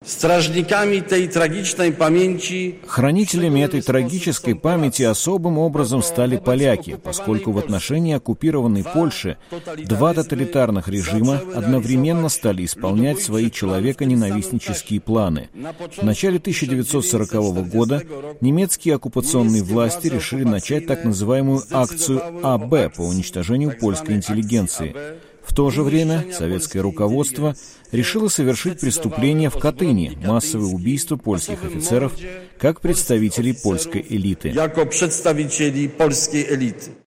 В Польше в мемориальном комплексе на месте бывшего нацистского лагеря смерти в Освенциме состоялась церемония по случаю 70-й годовщины его освобождения.